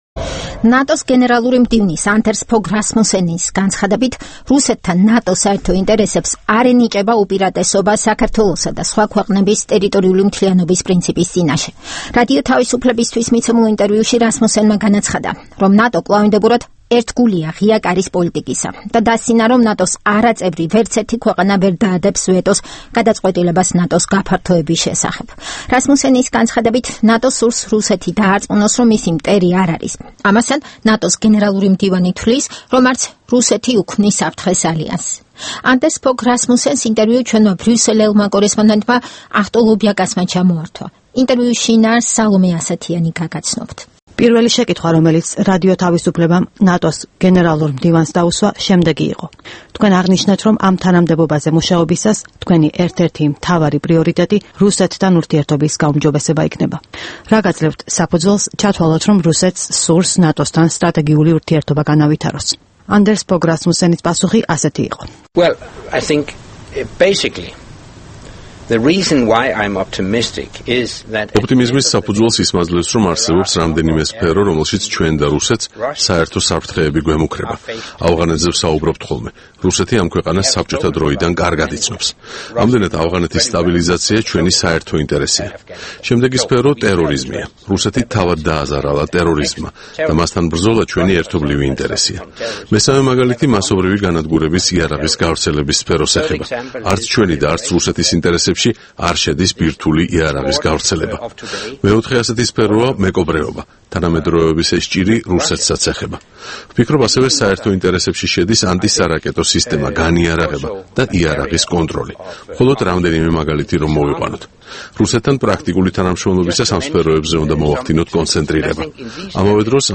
ინტერვიუ ნატოს გენერალურ მდივანთან